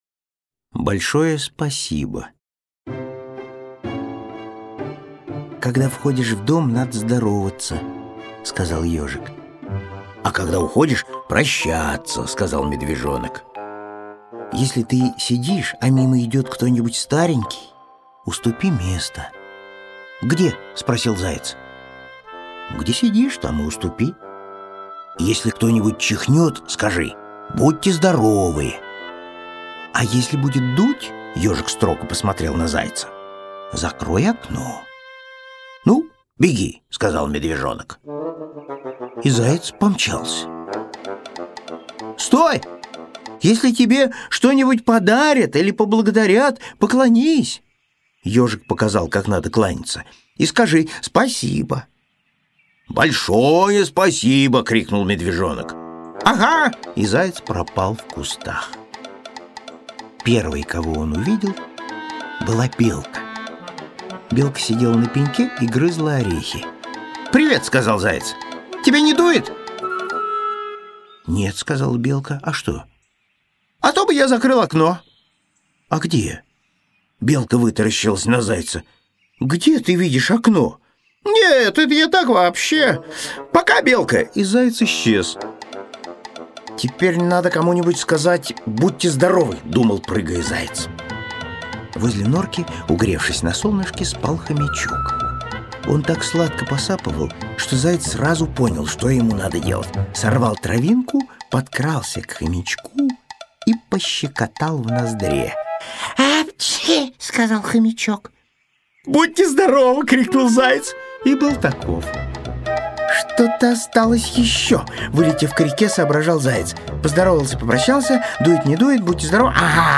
Аудиокнига Ёжик в тумане.